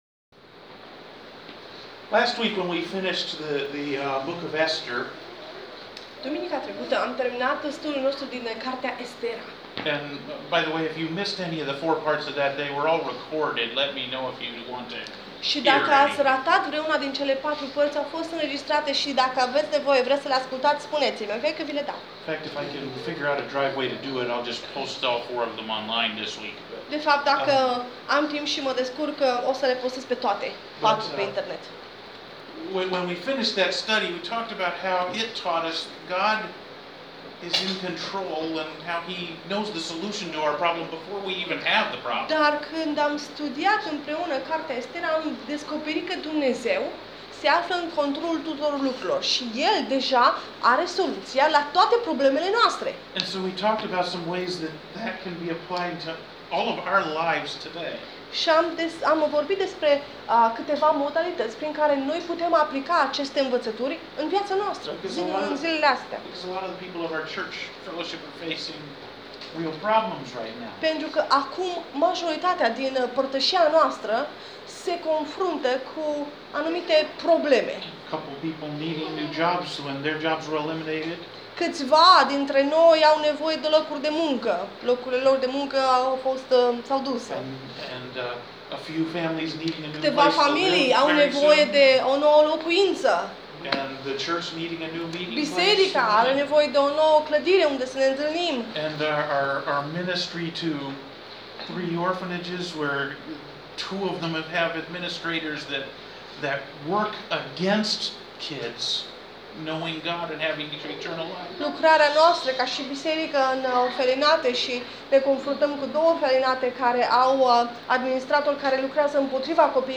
Sermon Audio 19 August 2018, Fapte 12
Sermon Audio Fapte 12, 1 Petru 5:7 -- 19 August 2018